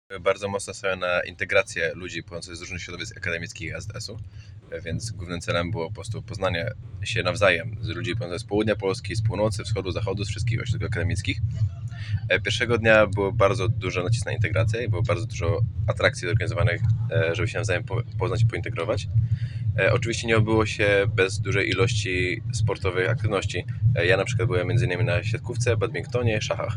Mieliśmy przyjemność porozmawiać z przedstawicielem z naszego lokalnego środowiska AZSu